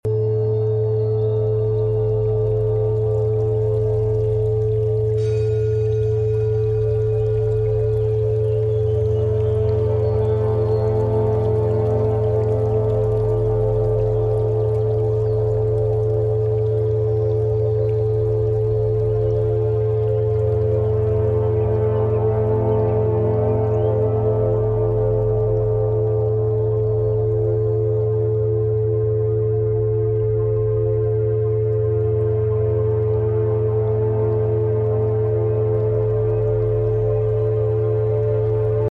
Designed with the powerful higher frequency sound of 432 Hz, this track is your natural gateway to a state of deep sleep frequency. Let the gentle vibrations wash over you, effectively working as soothing sounds to calm the mind and heart.
Embrace the calming resonance and rediscover the art of true relaxation.